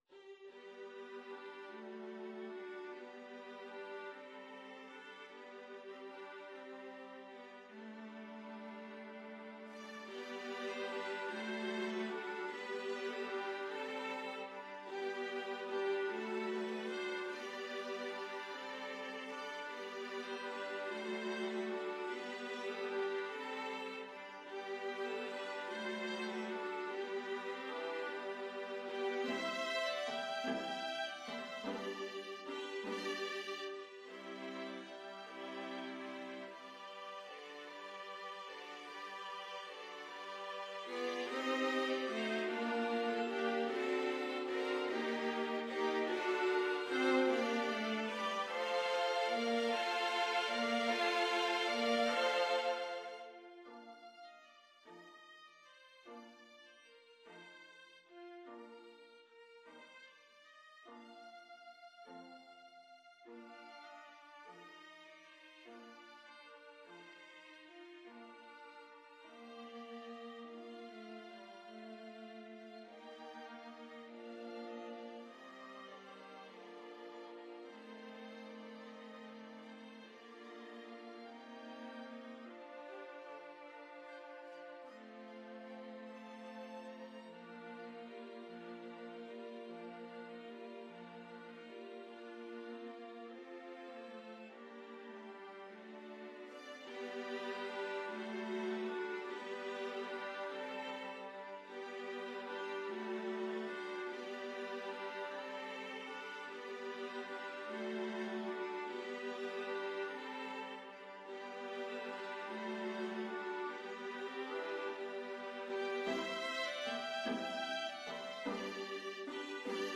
Free Sheet music for Viola Quartet
12/8 (View more 12/8 Music)
G major (Sounding Pitch) (View more G major Music for Viola Quartet )
Andante mosso . = c. 50
Viola Quartet  (View more Intermediate Viola Quartet Music)
Classical (View more Classical Viola Quartet Music)